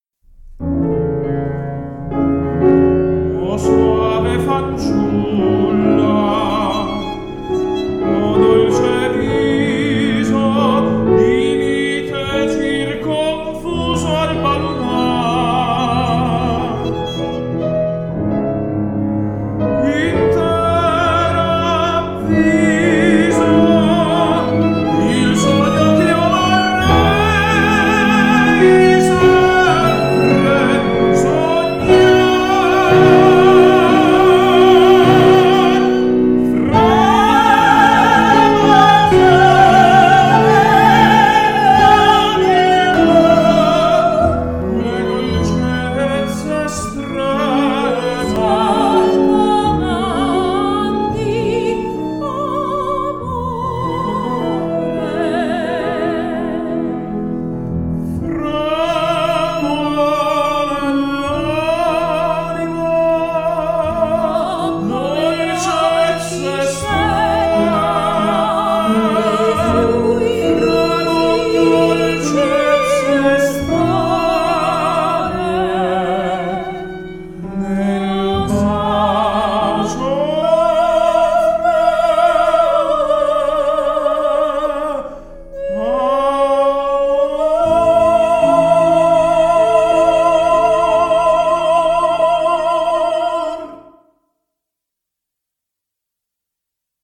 10 Gen 38 duetto O soave fanciulla ok